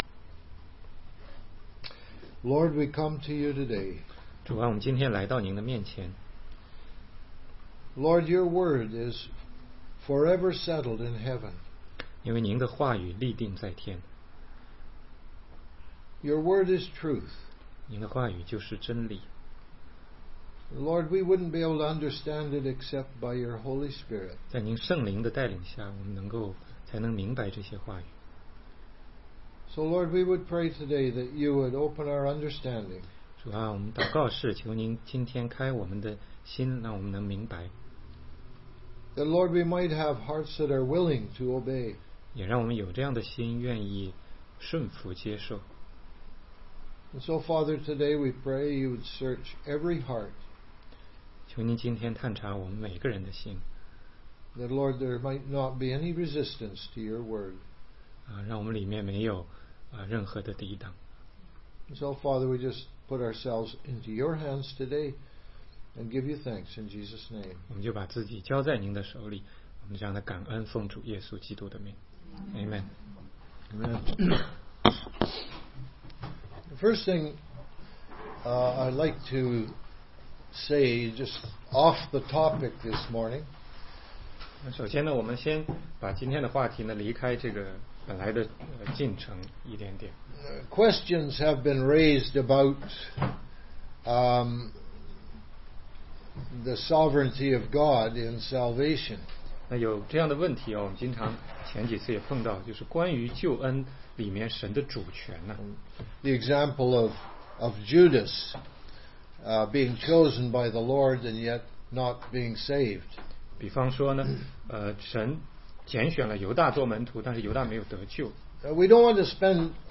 16街讲道录音 - 约翰福音7章16-18节